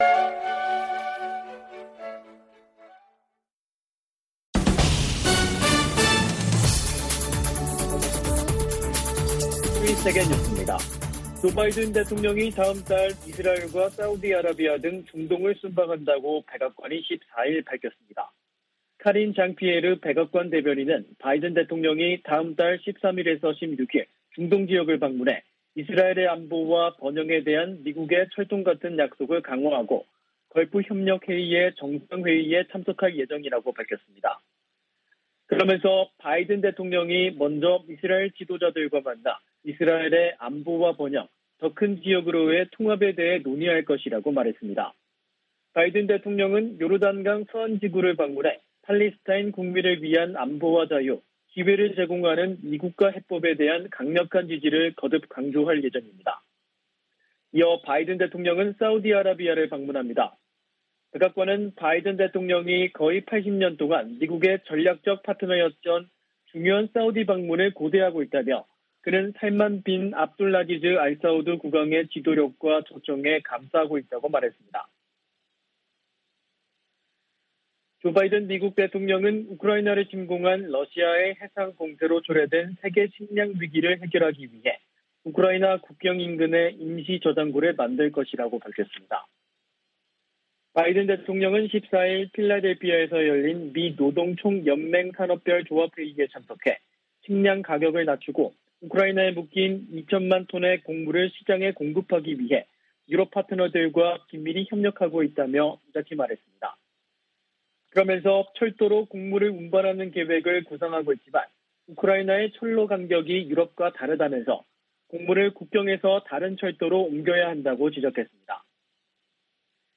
VOA 한국어 간판 뉴스 프로그램 '뉴스 투데이', 2022년 6월 15일 3부 방송입니다. 미국 정부 대북제재의 근거가 되는 '국가비상사태'가 다시 1년 연장됐습니다. 미 재무부 부장관은 북한의 거듭되는 무력시위에 응해 추가 제재 방안을 면밀히 검토하고 있다고 밝혔습니다. 미국은 한국·일본과 협의해 북한의 도발에 대한 장단기 군사대비태세를 조정할 것이라고 미 국방차관이 밝혔습니다.